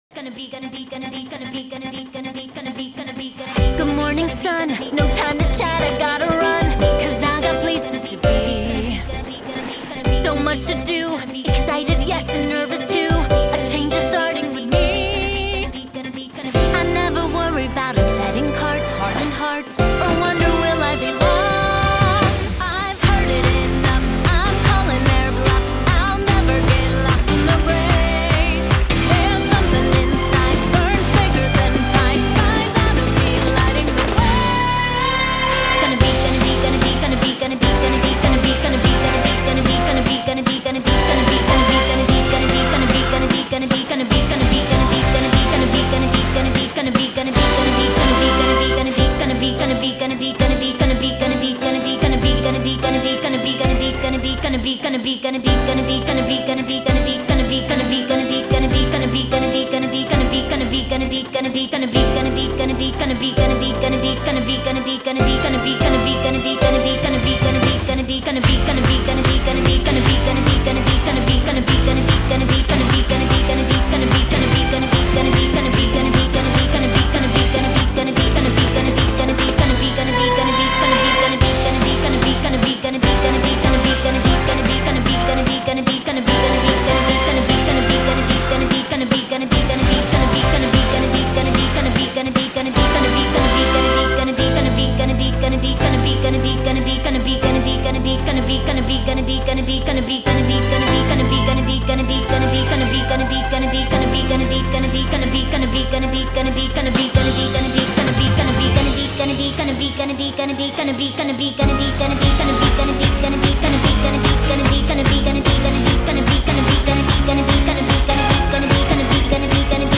My Average Sized Pony goalhorn